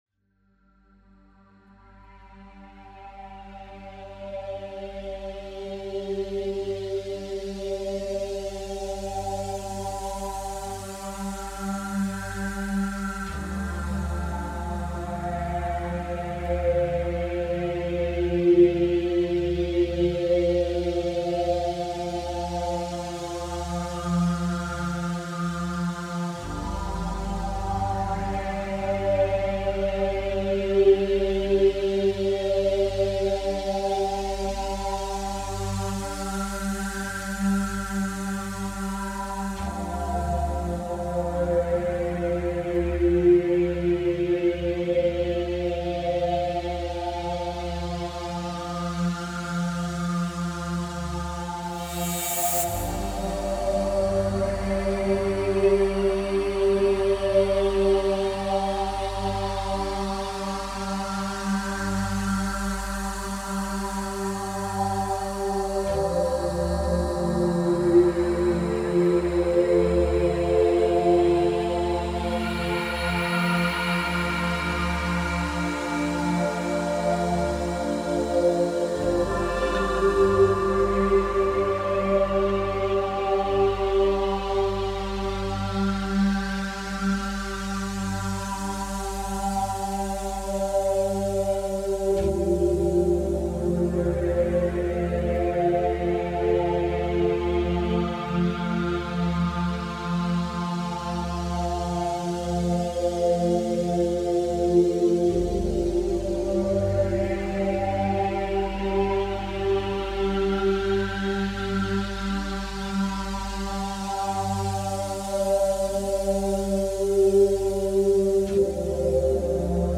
Genre: Electronic.